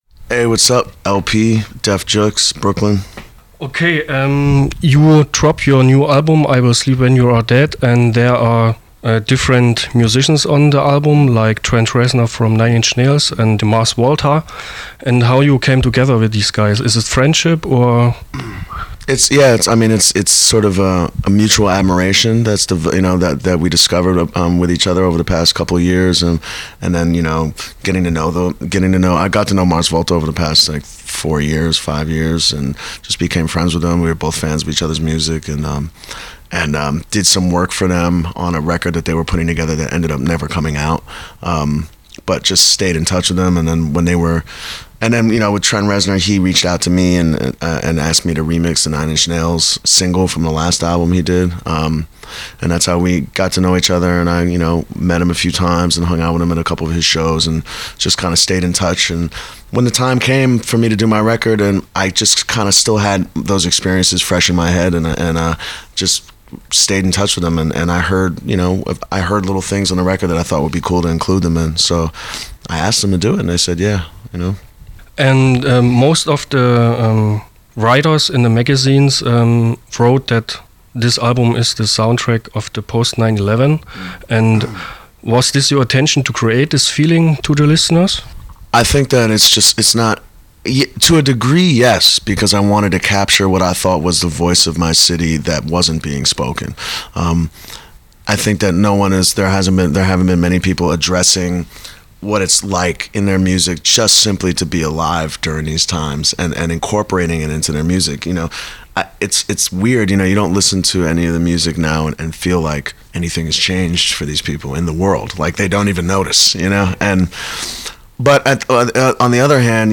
April 2007 in Köln (Sorry für die schlechte Soundqualität – Technik was fucked up!)
El-P Interview (02.04.2007 in Cologne)